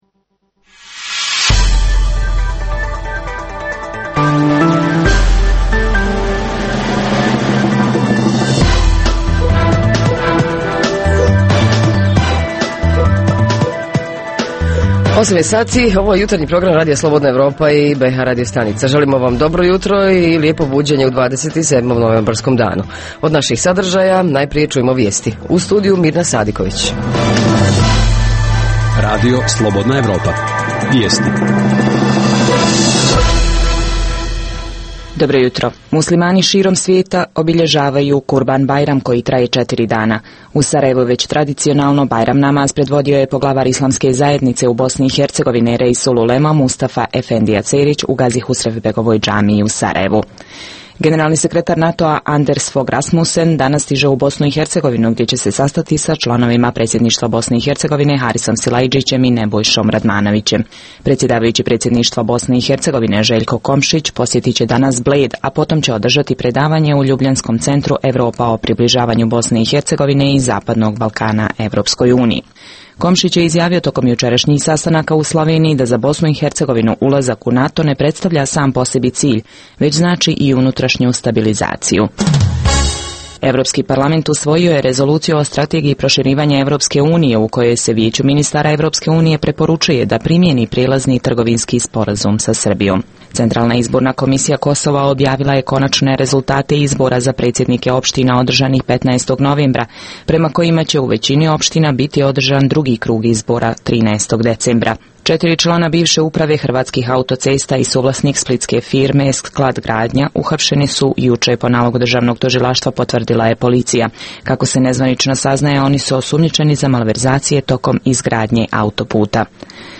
Govorimo o kvaliteti međuljudskih odnosa bez obzira da li je riječ o odnosima u porodici, komšiluku, na poslu, u trgovinama ili bilo gdje. Reporteri iz cijele BiH javljaju o najaktuelnijim događajima u njihovim sredinama.
Redovni sadržaji jutarnjeg programa za BiH su i vijesti i muzika.